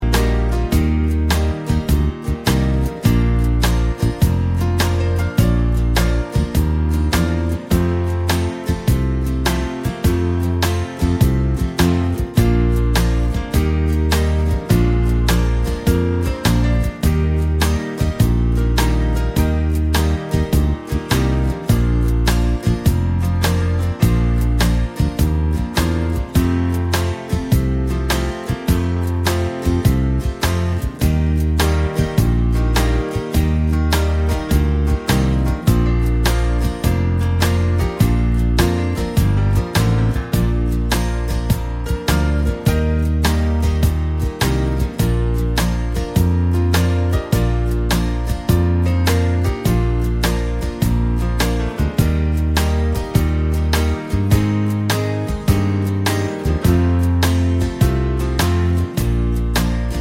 One Semitone Up Pop (1960s) 2:20 Buy £1.50